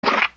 fart2.wav